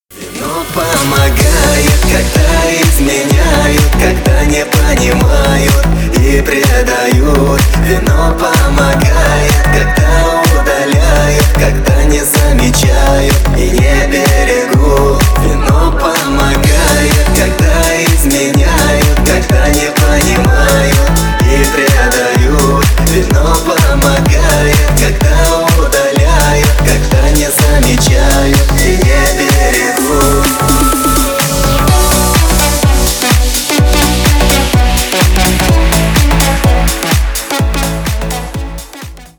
Ремикс # Поп Музыка
грустные